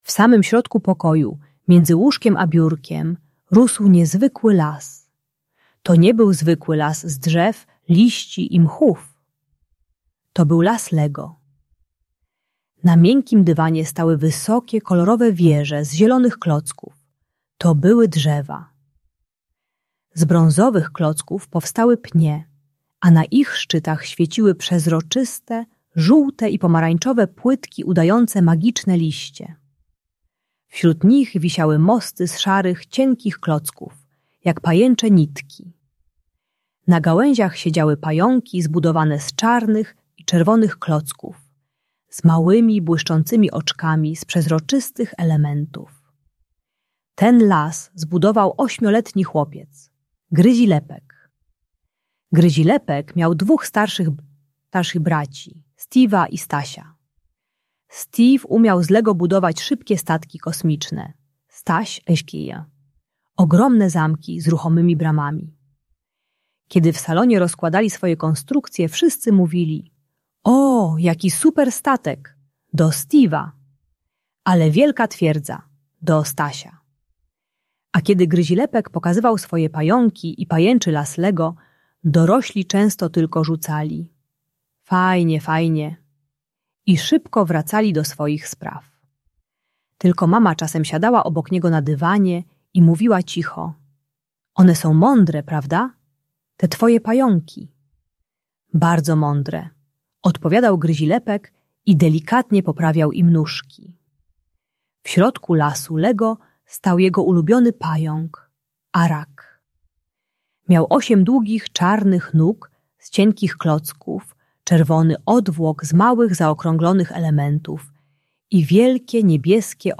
Audiobajka o radzeniu sobie z dokuczaniem rówieśników i asertywności dla młodszych uczniów.